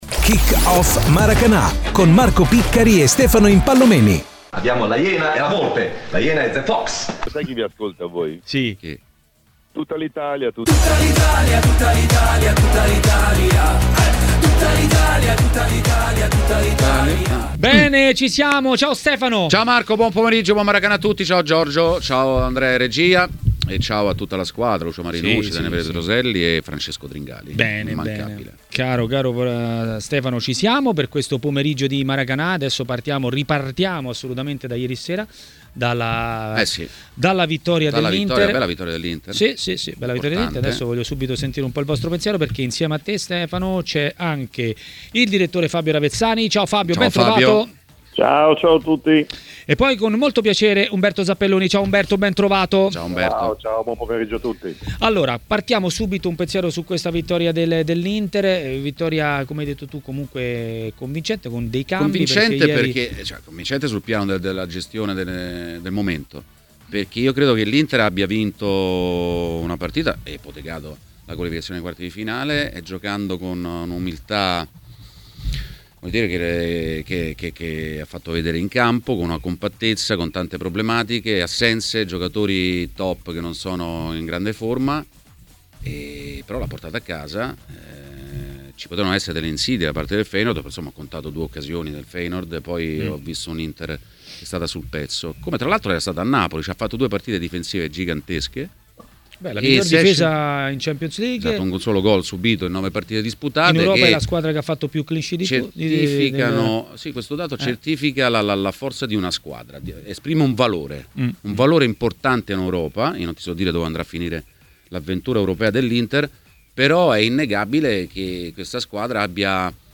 è intervenuto in diretta a TMW Radio, durante Maracanà.